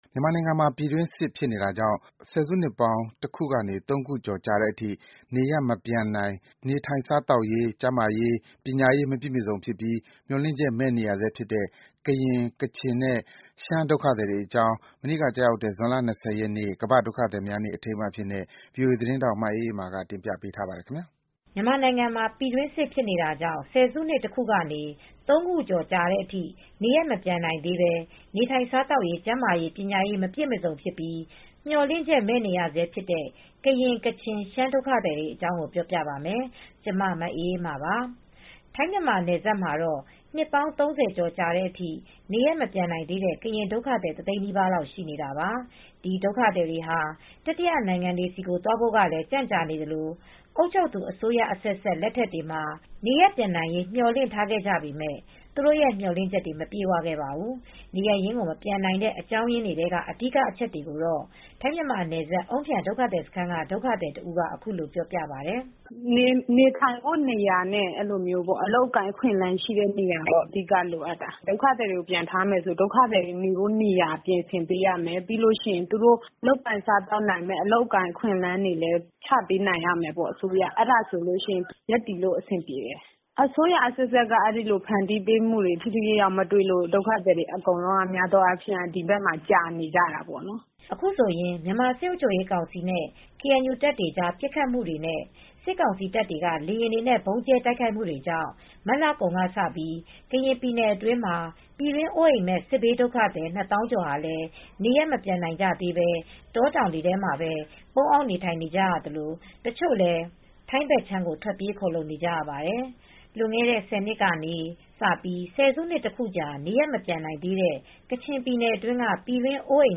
ဆယ်စုနှစ်နဲ့ကြာ မျှော်လင့်ချက်မဲ့ဒုက္ခသည်များ(ဒုက္ခသည်နေ့အထူးသတင်းဆောင်းပါး)